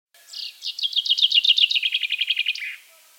Большинство песен зяблика устроено так: вначале идет одна или несколько разных трелей (серий одинаковых нот), а в конце звучит заключительная громкая фраза — «росчерк».
Представьте себя Питером Марлером, послушайте записи песен шести зябликов и подберите для каждой из них трели и «росчерк» из предложенных схем.
chaffinch5.mp3